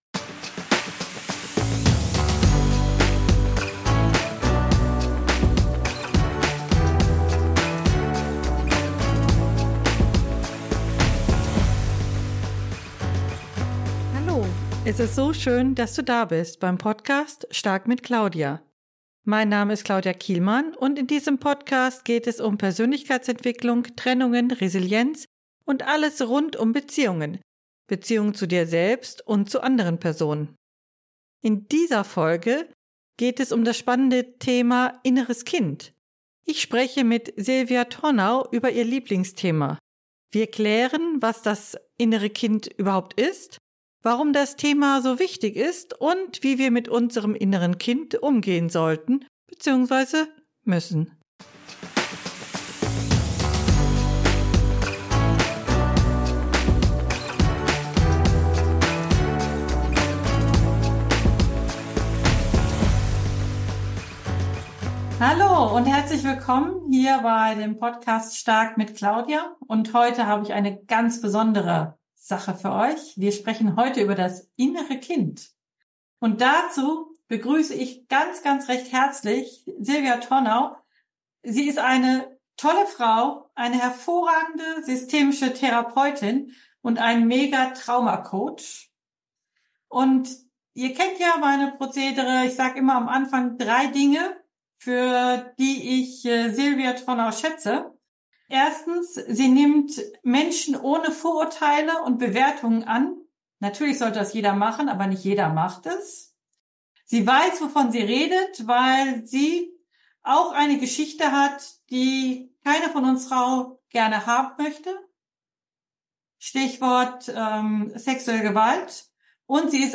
#043 Das innere Kind - Interview